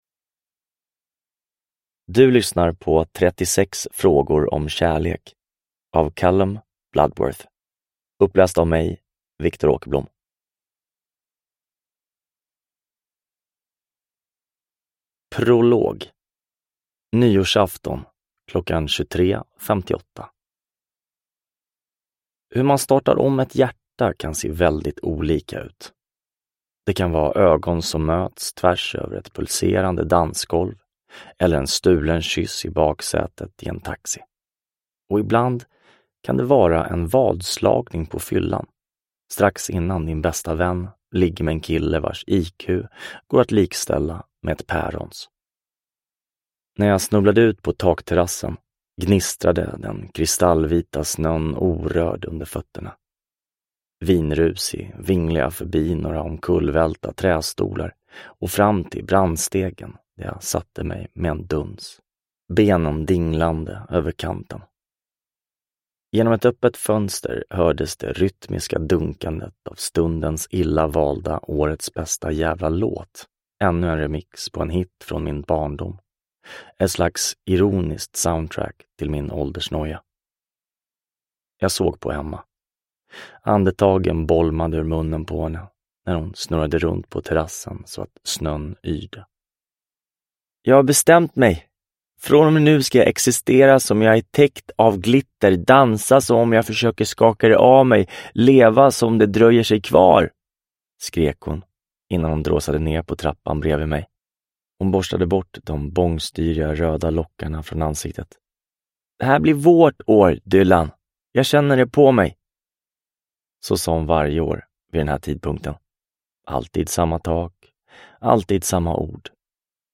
36 frågor om kärlek – Ljudbok – Laddas ner